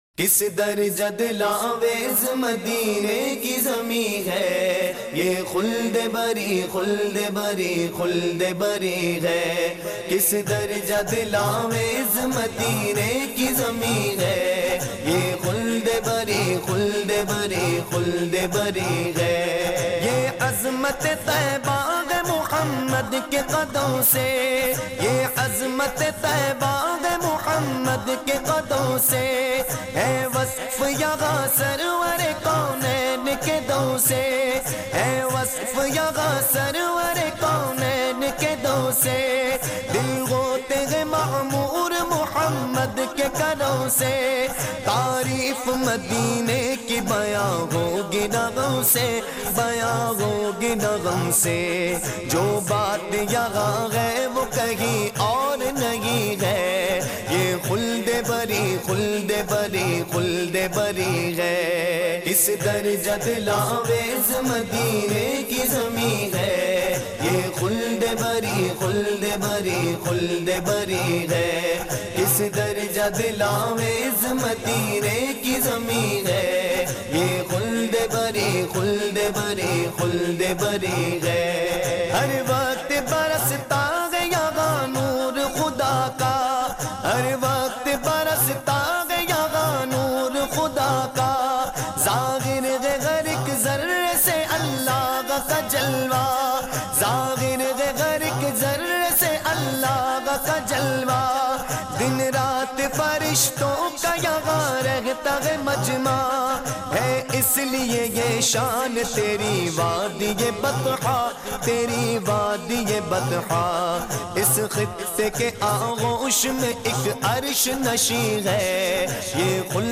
Naat